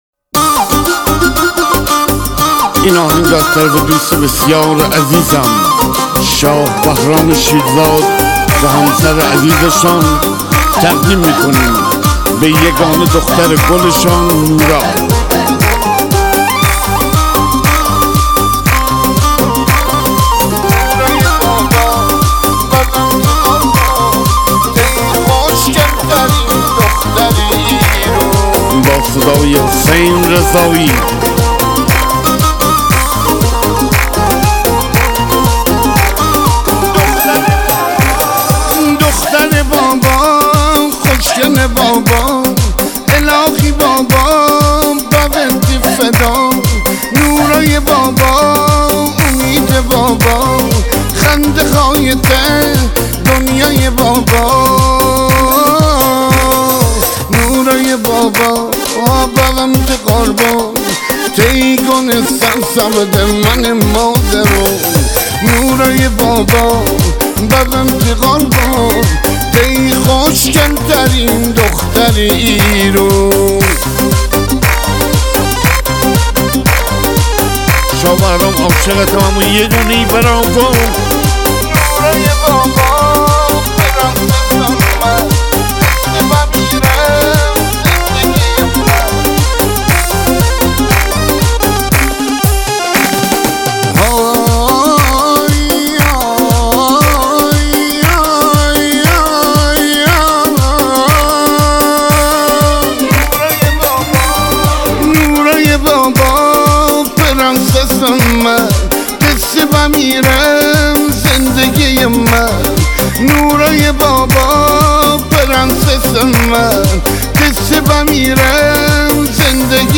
دانلود آهنگ شمالی